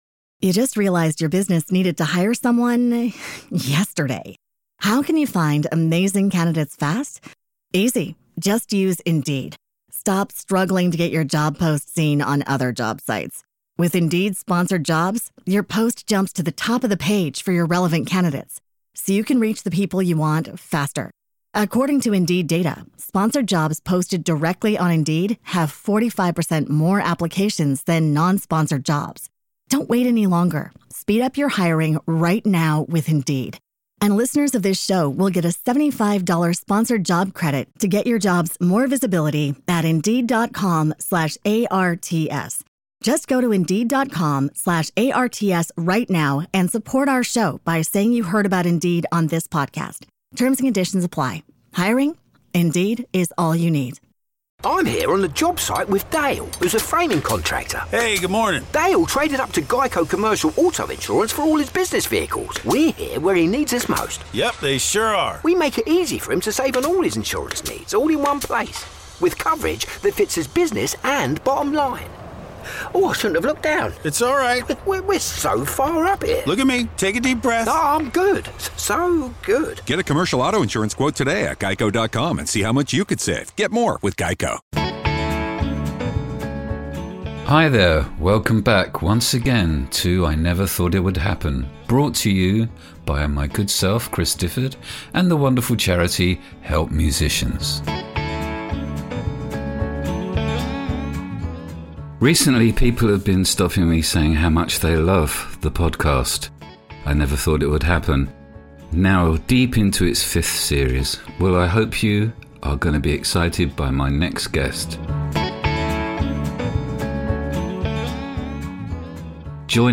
He said, you know the great thing about music Craig is that you’ll never really know anything about it" - Craig Armstrong This episode features a fascinating conversation with the multi-genre spanning musician and composer Craig Armstrong. Craig has collaborated with huge artists like U2, Madonna and Massive Attack, toured with Ultravox in their mid-Eighties pomp and has written for The Royal Shakespeare Company and scored films like Baz Luhrmann's 'Romeo + Juliet'.